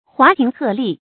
华亭鹤唳 huà tíng hè lì 成语解释 华亭谷的鹤叫声。